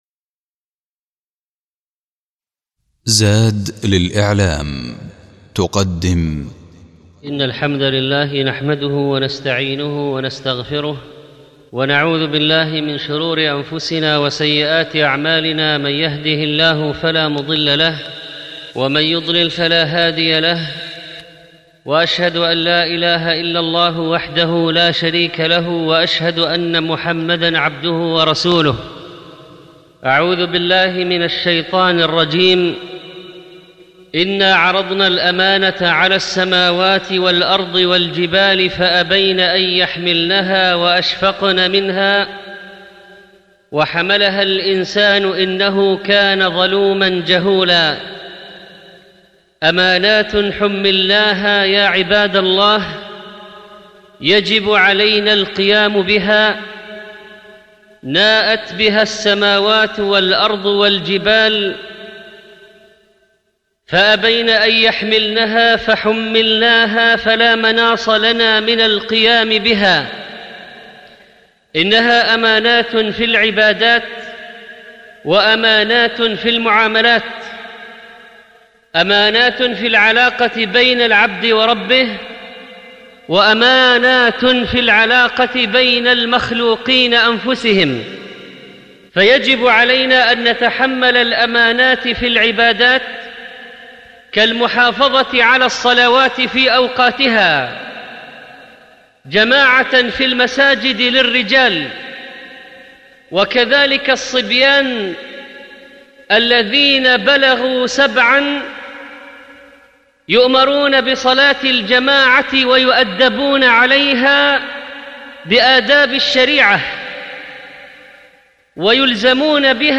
الموقع الرسمي للشيخ محمد صالح المنجد يحوي جميع الدروس العلمية والمحاضرات والخطب والبرامج التلفزيونية للشيخ
الخطبة الأولى